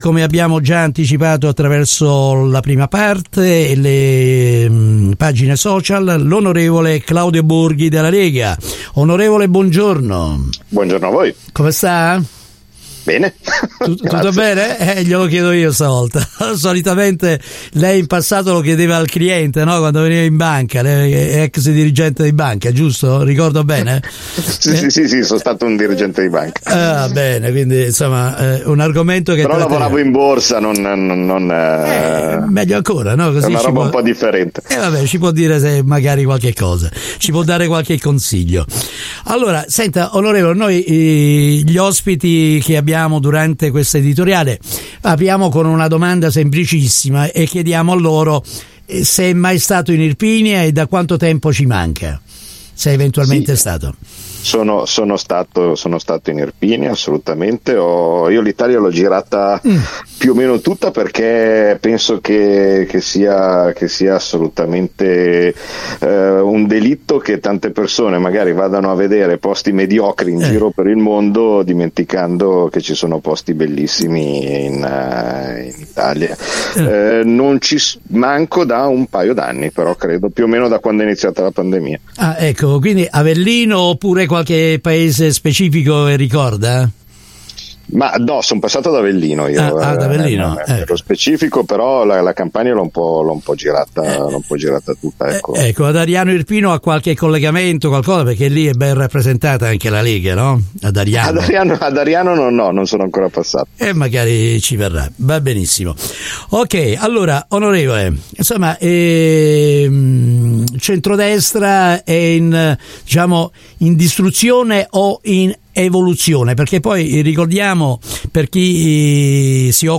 Così Claudio Borghi, deputato della Lega, ospite di Radio Ufita, sul tema del centrodestra.
INTERVISTA-CLAUDIO-BORGHI-QUIRINALE.mp3